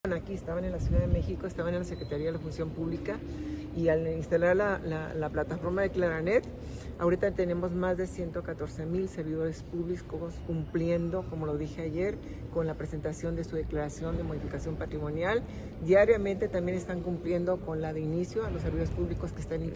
AUDIO: MARÍA DE LOS AÁNGELES ALVÁREZ, SECRETARÍA DE LA FUNCIÓN PÚBLICA (SFP)